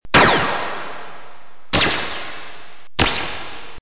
Photon shot twice
Category: Sound FX   Right: Personal
Tags: Photon Sounds Photon Sound Photon clips Sci-fi Sound effects